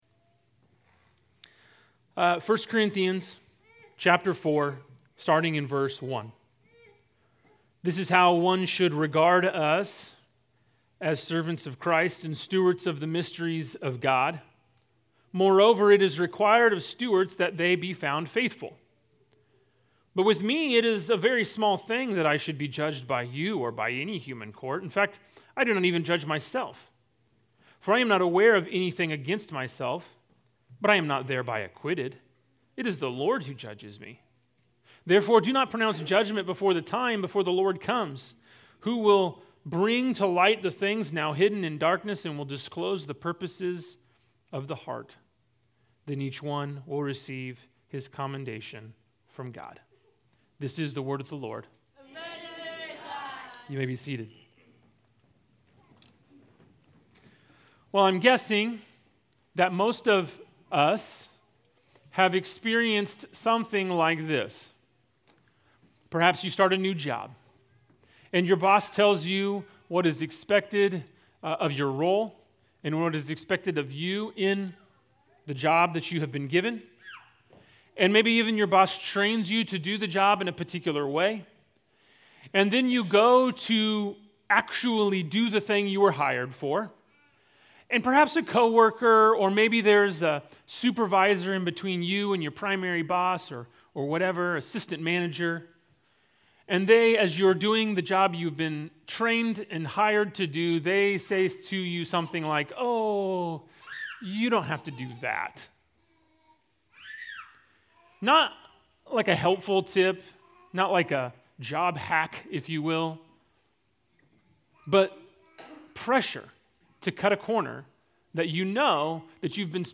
Sermons from Proclaim Church